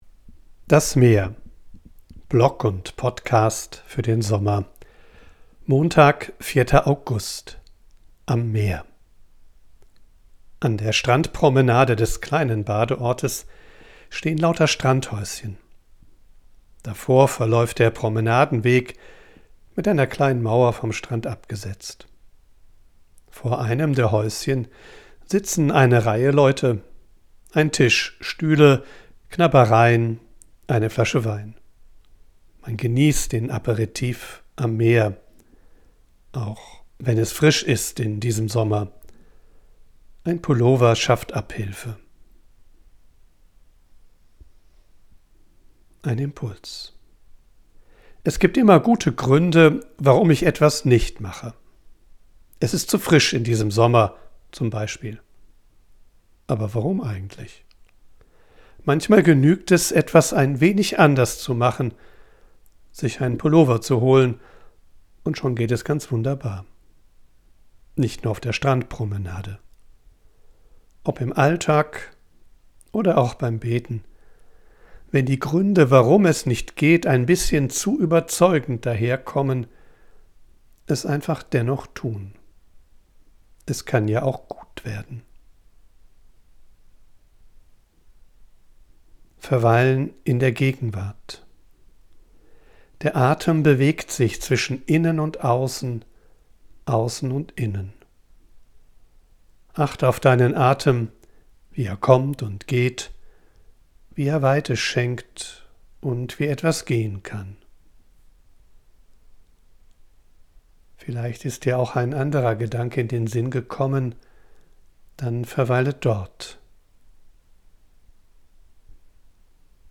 Ich bin am Meer und sammle Eindrücke und Ideen.
von unterwegs aufnehme, ist die Audioqualität begrenzt.
mischt sie mitunter eine echte Möwe und Meeresrauschen in die